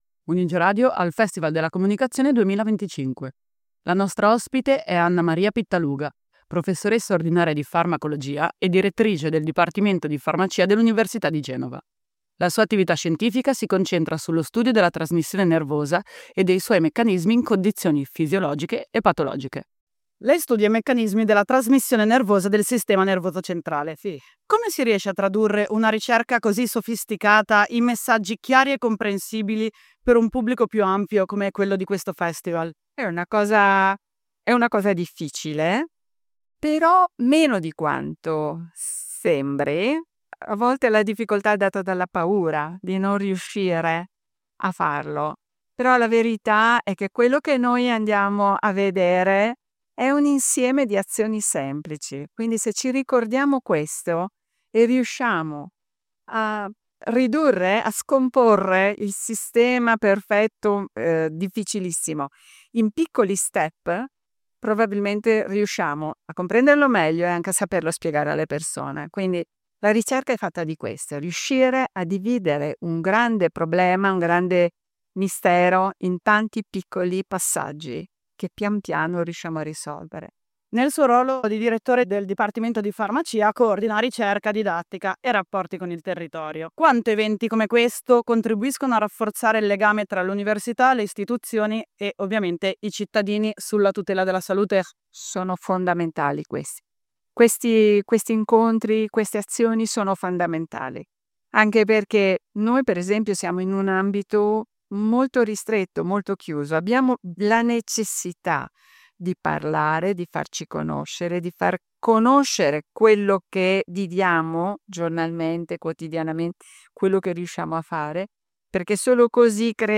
UniGE al Festival della Comunicazione 2025